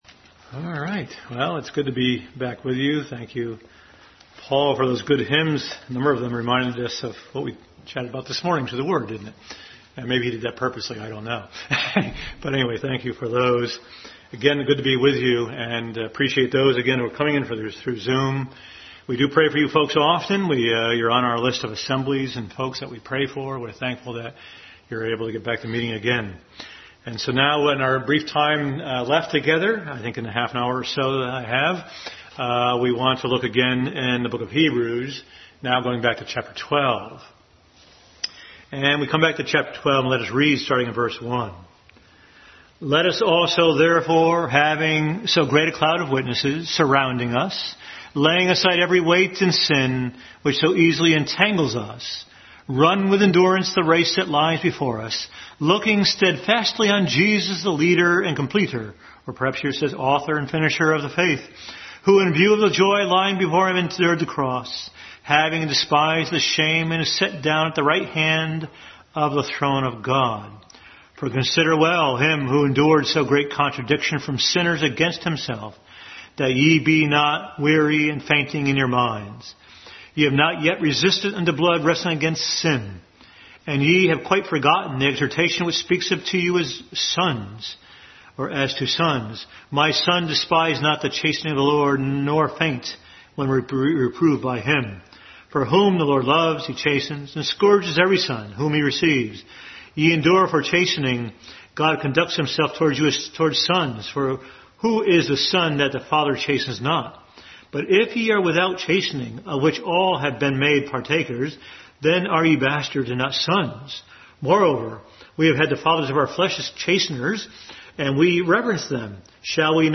Bible Text: Hebrews 12:1-11 | Family Bible Hour message.